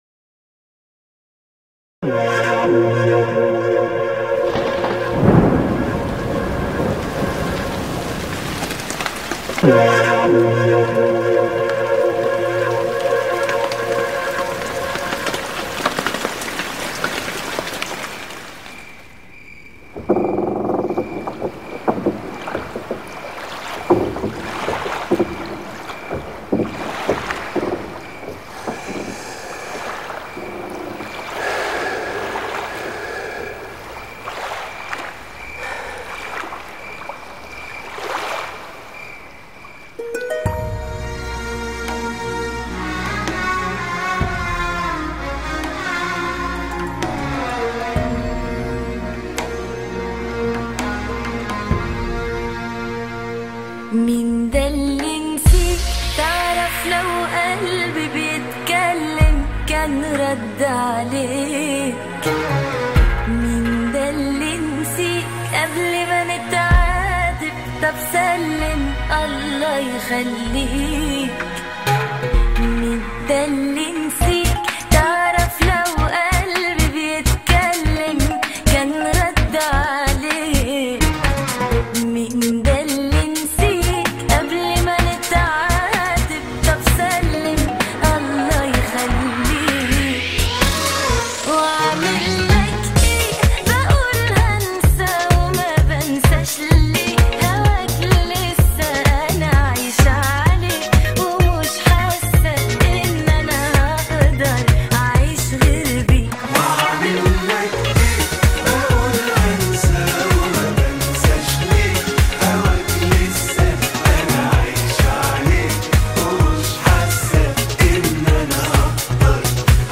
آهنگ ترند اینستاگرام عربی
دانلود آهنگ شاد قشنگ